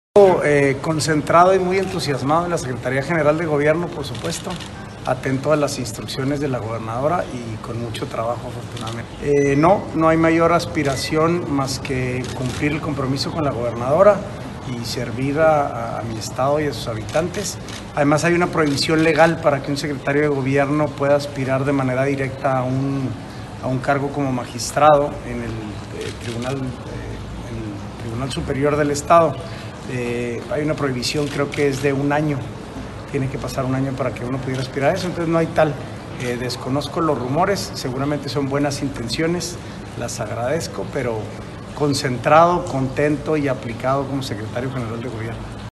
AUDIO: SANTIAGO DE LA PEÑA GRAJEDA, SECRETARÍA GENERAL DE GOBIERNO (SGG)